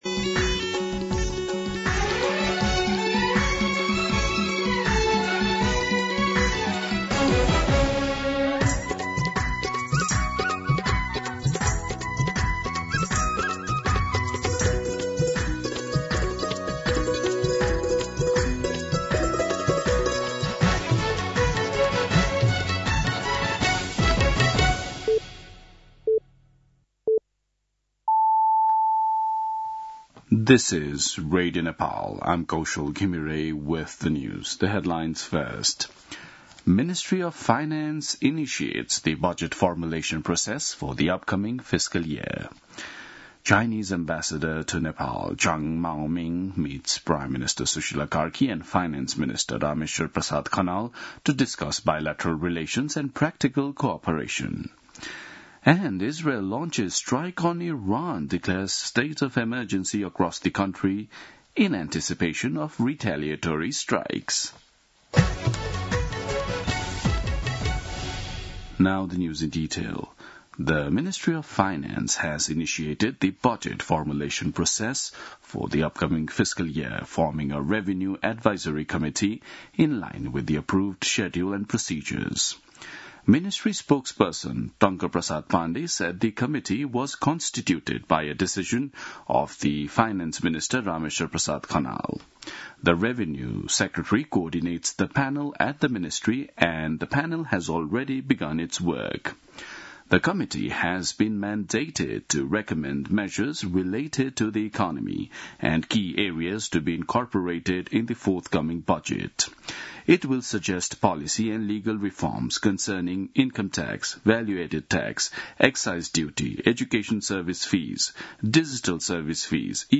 दिउँसो २ बजेको अङ्ग्रेजी समाचार : १६ फागुन , २०८२
2pm-English-News-11-16.mp3